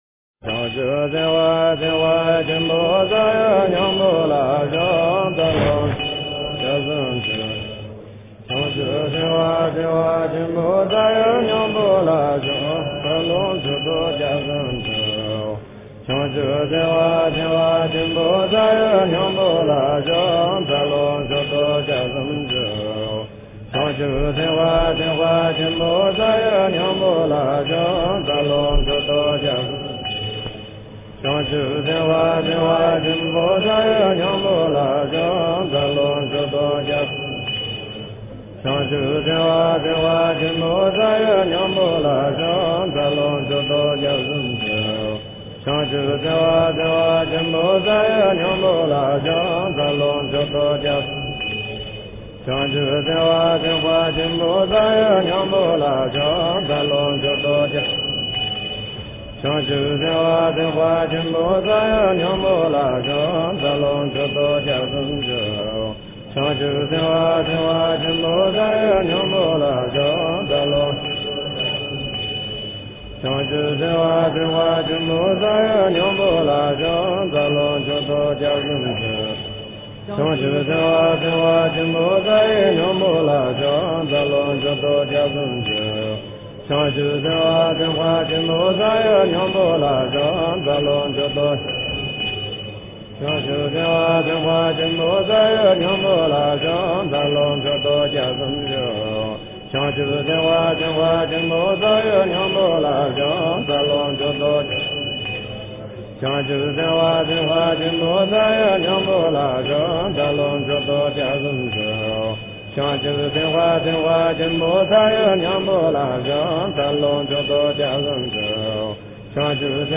十轮地藏王菩萨心咒--五明佛学院僧众
十轮地藏王菩萨心咒--五明佛学院僧众 经忏 十轮地藏王菩萨心咒--五明佛学院僧众 点我： 标签: 佛音 经忏 佛教音乐 返回列表 上一篇： 安土地真言--未知 下一篇： 般若波罗蜜多心经--新韵传音 相关文章 《妙法莲华经》如来寿量品第十六--佚名 《妙法莲华经》如来寿量品第十六--佚名...